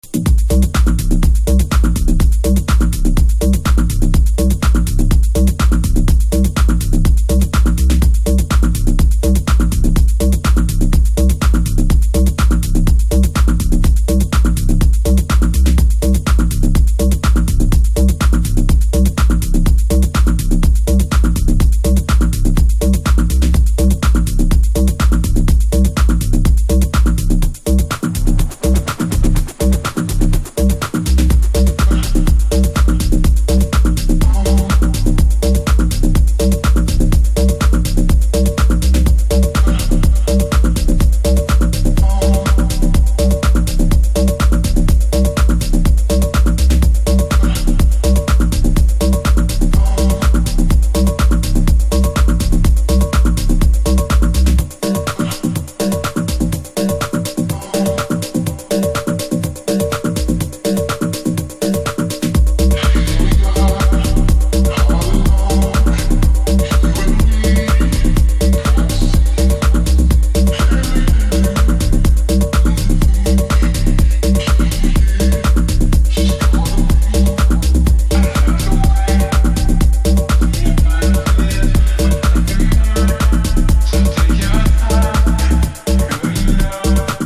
走ったビートにヘヴィーなベースが組み合わさった完全フロア向きトラック！